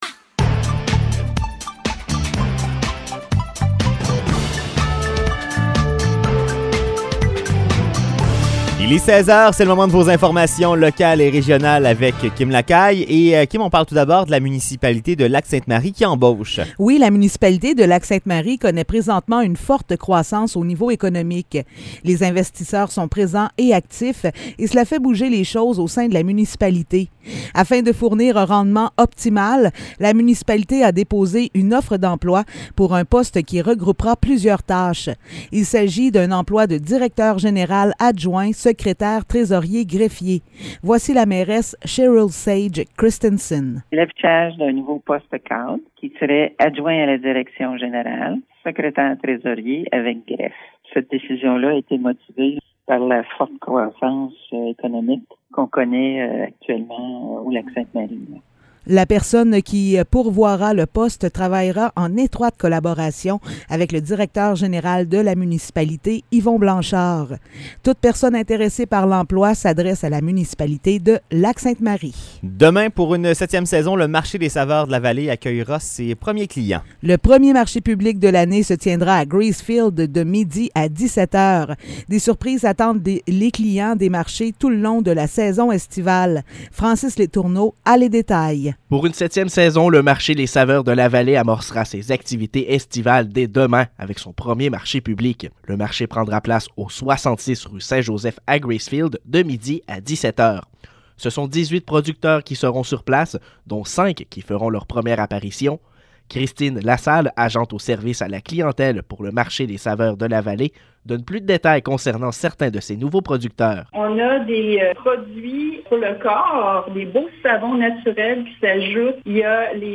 Nouvelles locales - 16 juin 2022 - 16 h